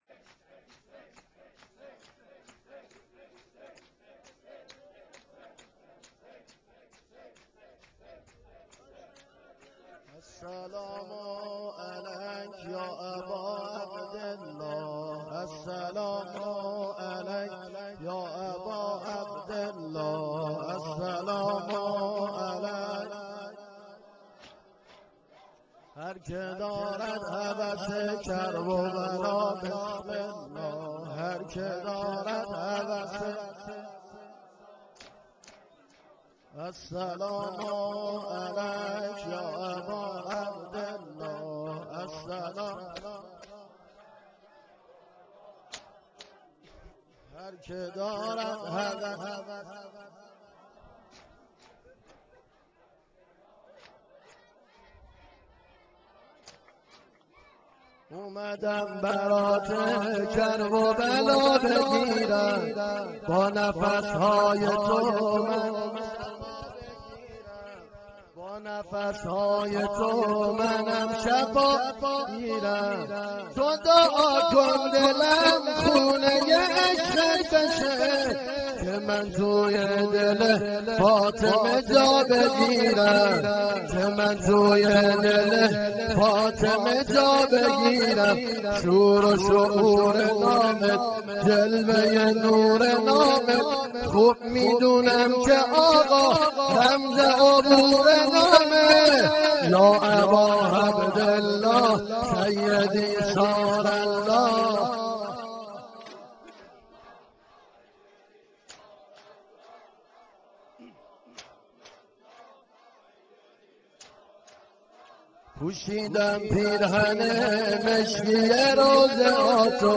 nohe-shab-7.wma